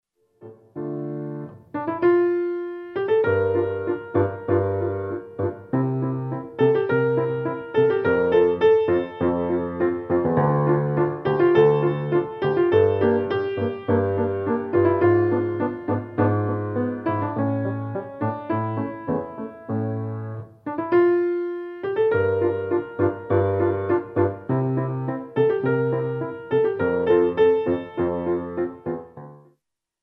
MODERATE